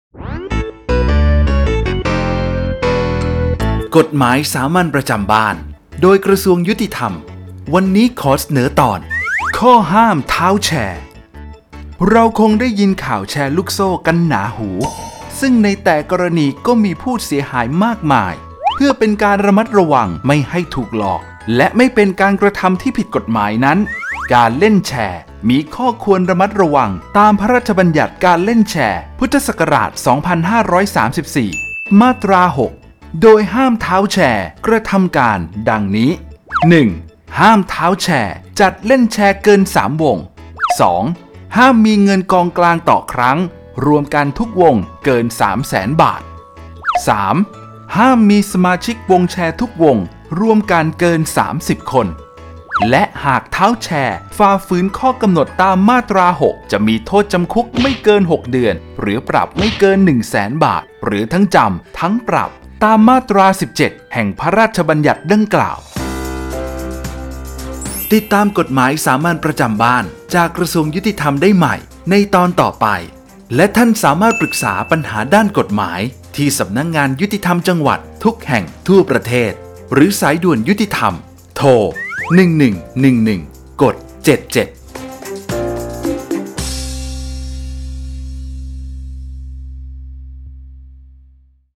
กฎหมายสามัญประจำบ้าน ฉบับภาษาท้องถิ่น ภาคกลาง ตอนข้อห้ามท้าวแชร์
ลักษณะของสื่อ :   คลิปเสียง, บรรยาย